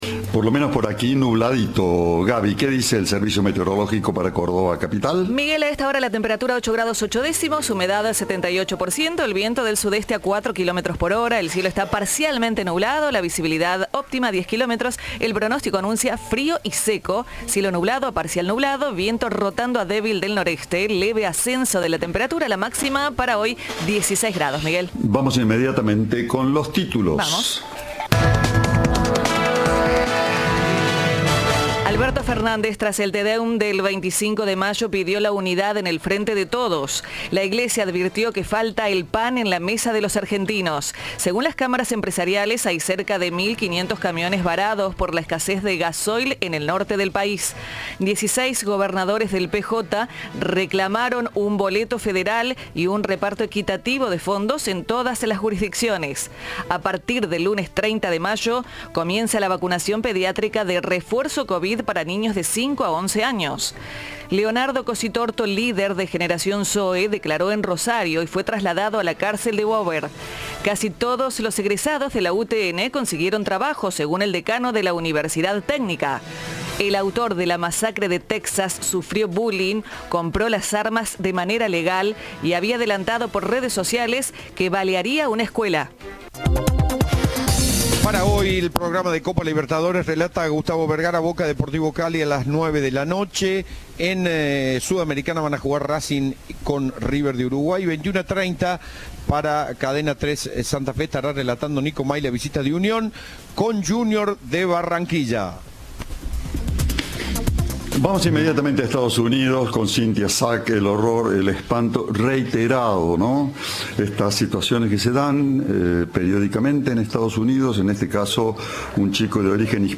Así lo reveló el gobernador de Texas, Greg Abbott, en una conferencia de prensa que brindó este miércoles.